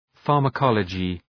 Shkrimi fonetik{,fɑ:rmə’kɒlədʒı}